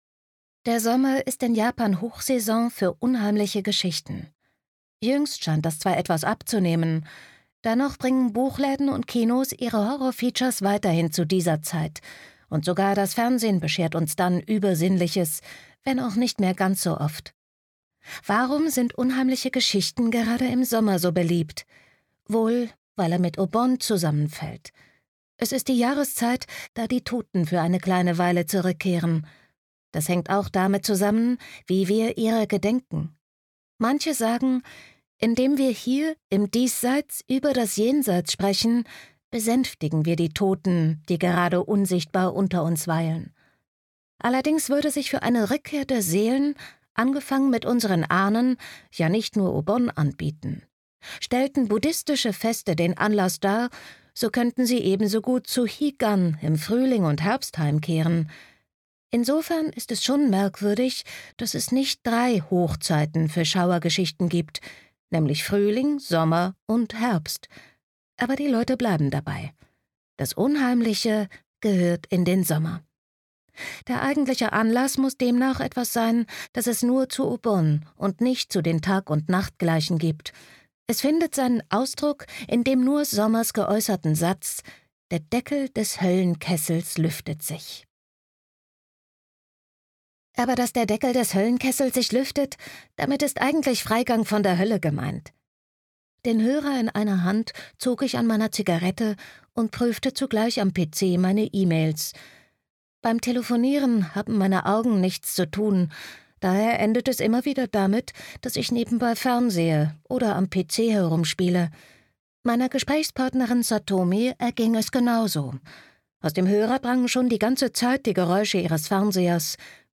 Gekürzt Autorisierte, d.h. von Autor:innen und / oder Verlagen freigegebene, bearbeitete Fassung.
Switch Studio, Berlin, 2022/ argon verlag